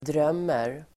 Uttal: [dr'öm:er]